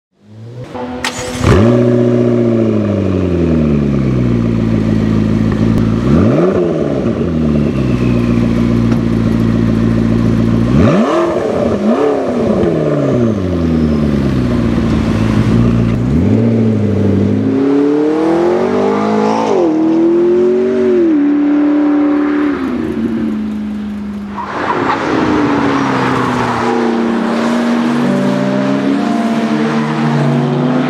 ENGINE SIZE 3.8L V8 Twin-Turbo
McLaren-MP4-12C.mp3